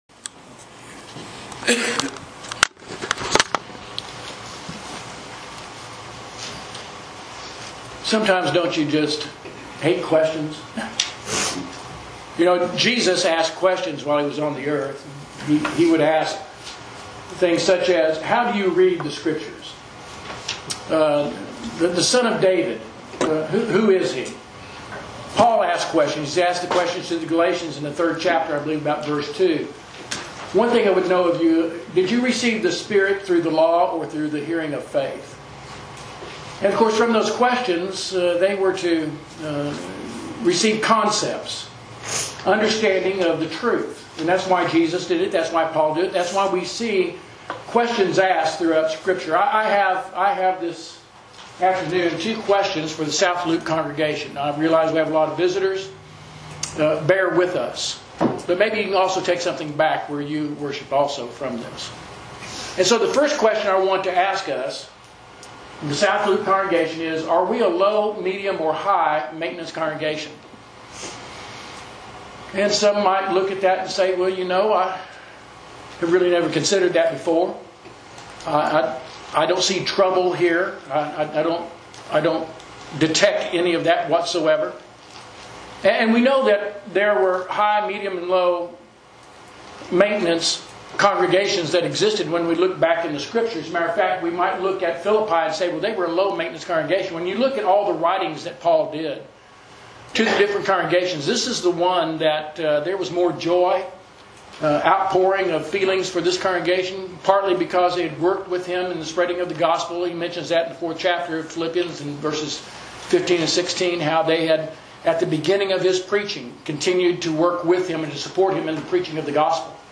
Sermons – Page 32 – South Loop church of Christ